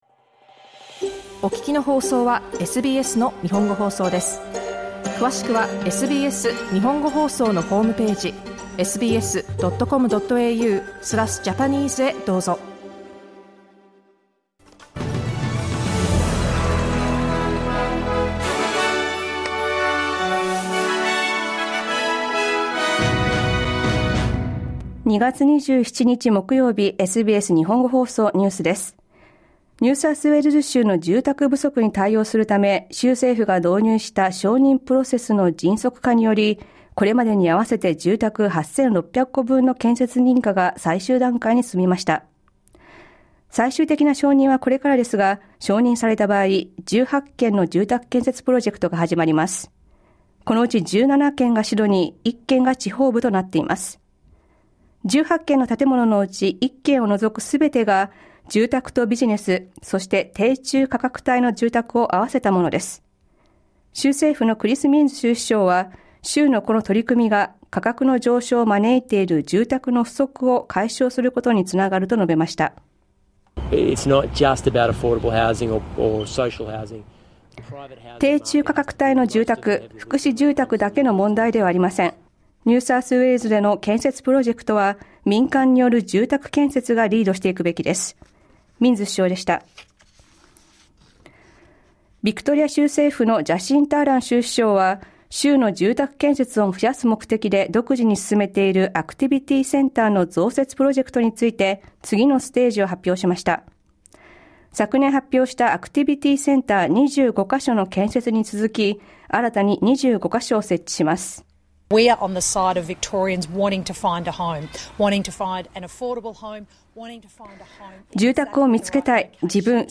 ニューサウスウェールズ州とビクトリア州それぞれの政府による住宅建設支援策の続報、またゴールドコーストのメインビーチで不審物が見つかり、現場近くが立入禁止になっています。午後１時から放送されたラジオ番組のニュース部分をお届けします。